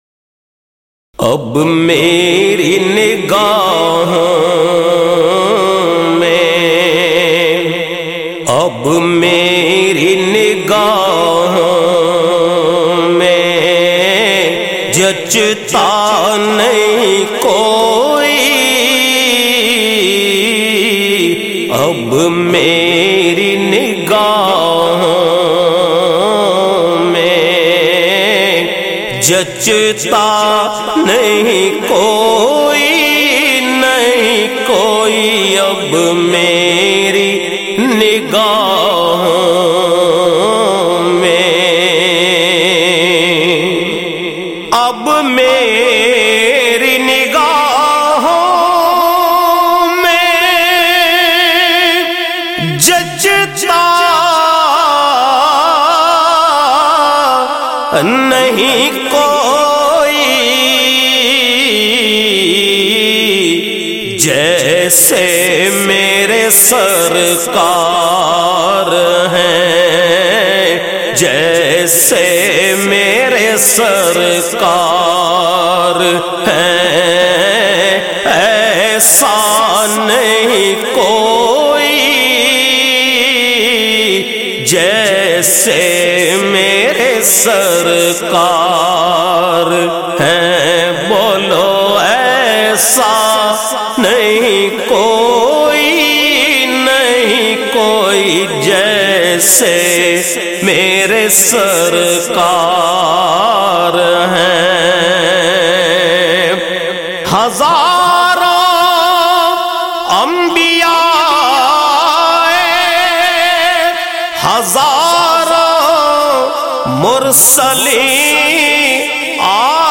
Naat Sharif
in a Heart-Touching Voice